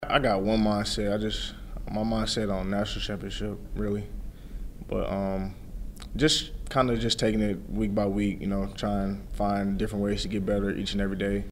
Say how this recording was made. Mizzou player cuts from SEC Media Days.